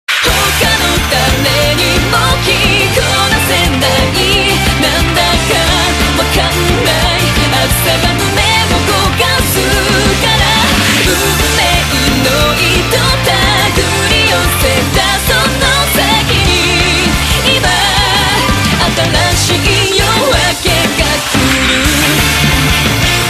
M4R铃声, MP3铃声, 日韩歌曲 65 首发日期：2018-05-15 11:00 星期二